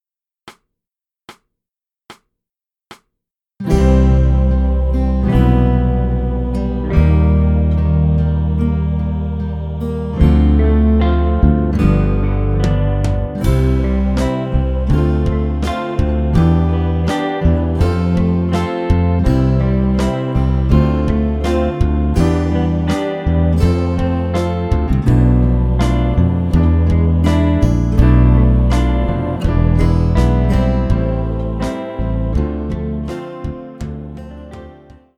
Gattung: Flöte mit Online-Audio
Besetzung: Instrumentalnoten für Flöte